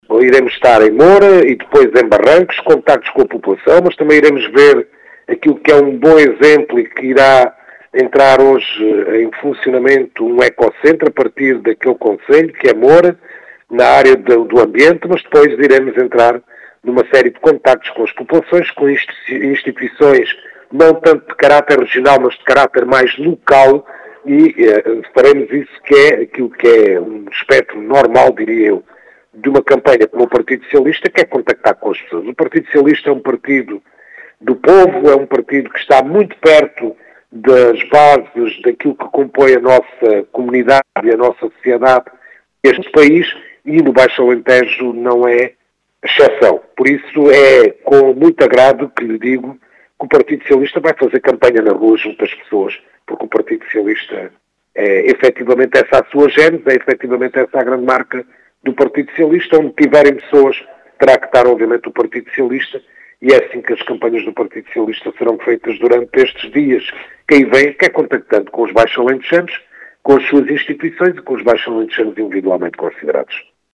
As explicações são do cabeça de lista do PS, pelo distrito de Beja, Nélson Brito, que afirma que o PS quer estar “junto das pessoas”.